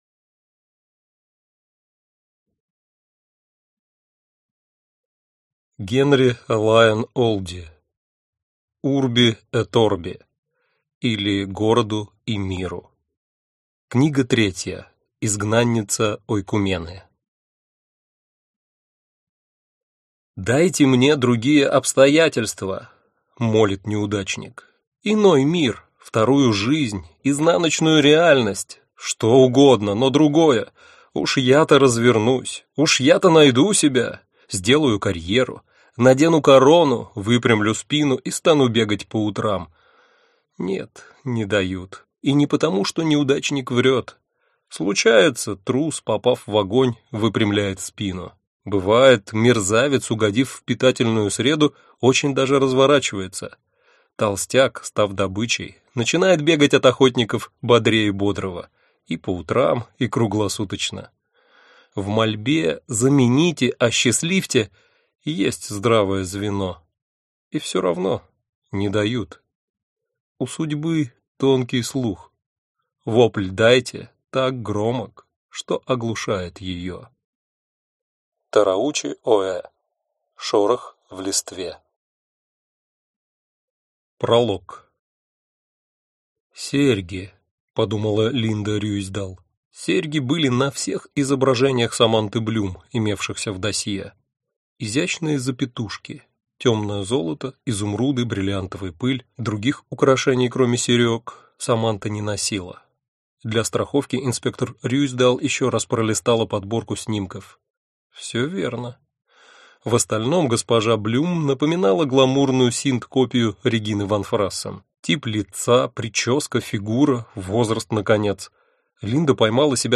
Аудиокнига Изгнанница Ойкумены | Библиотека аудиокниг